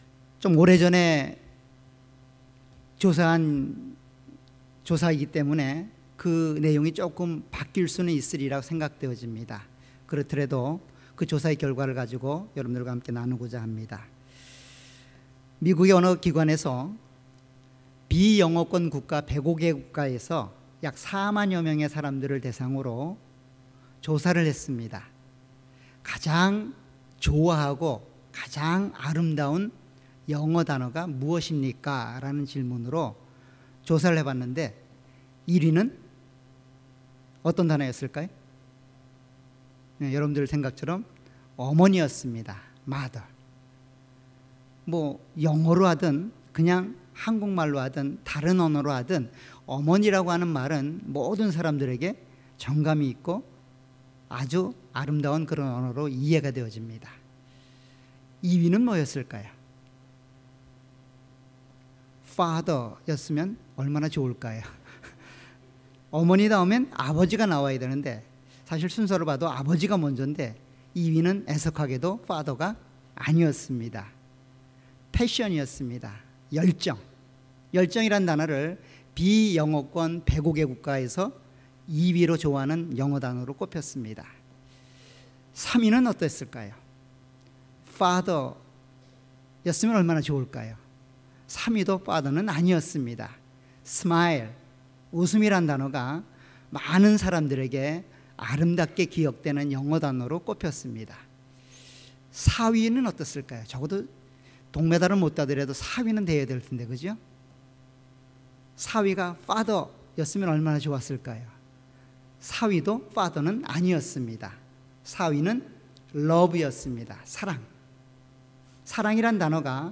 All Sermons
주일예배.Sunday